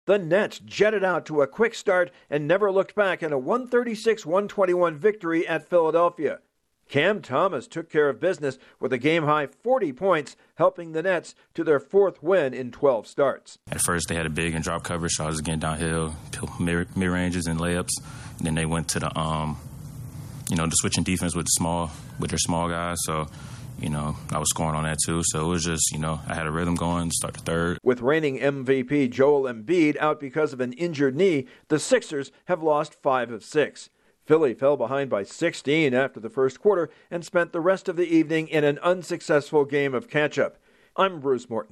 A 40-point performance is among the highlights in the Nets' thumping of the 76ers. Correspondent